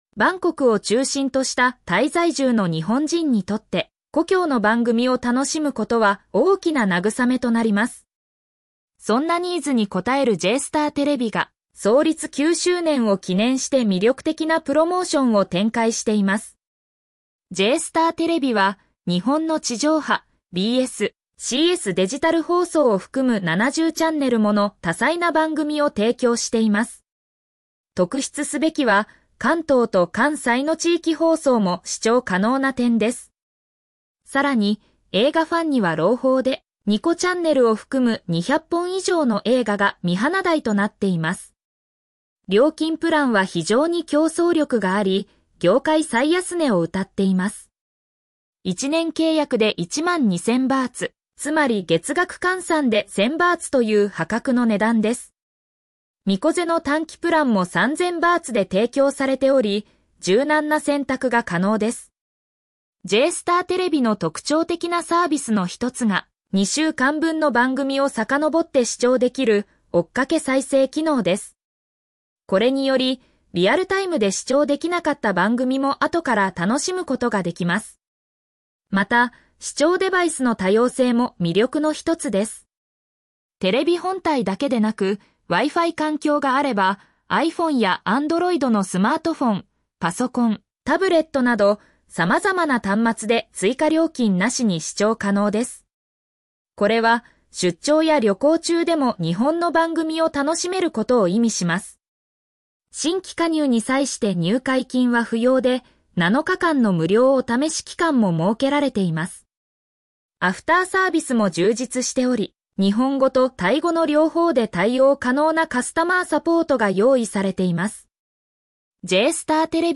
読み上げ